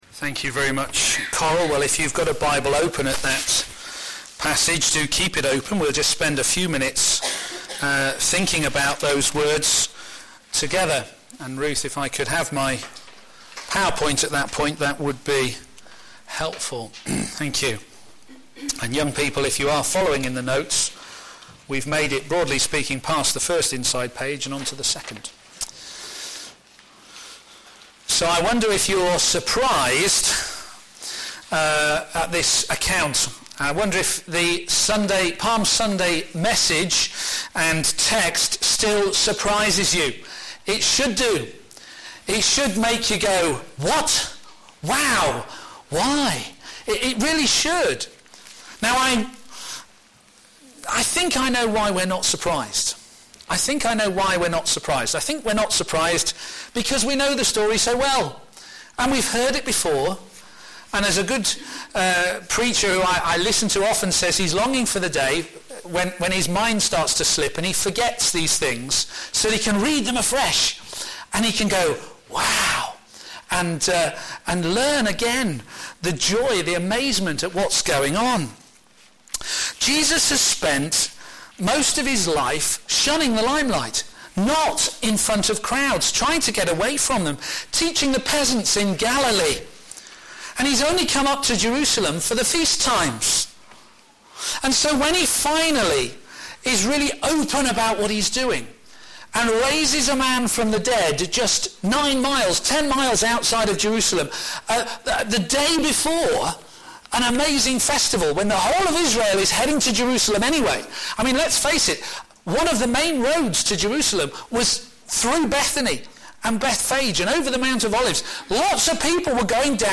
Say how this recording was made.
a.m. Service